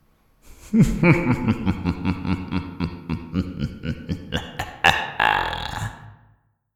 evil-laugh
bad-guy bad-man creepy echo-laugh evil evil-laugh evil-man ghost sound effect free sound royalty free Funny